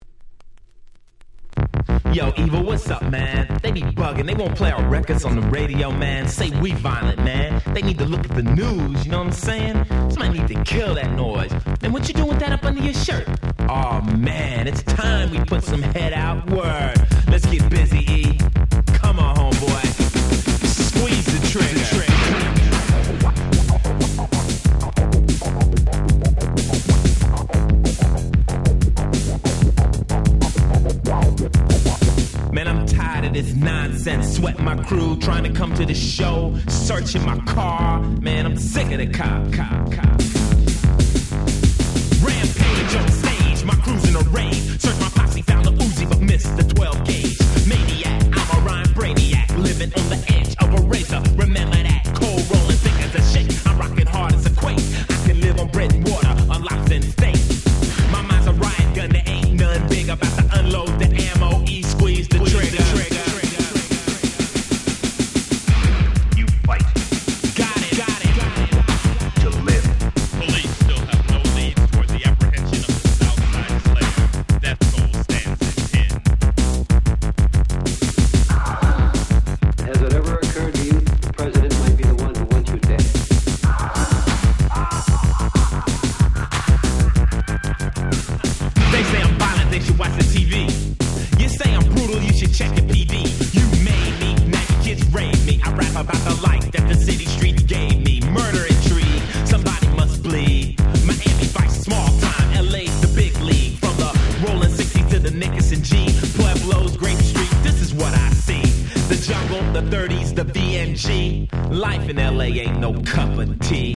80's Hip Hop Super Classics !!
アイスティー Boom Bap ブーンバップ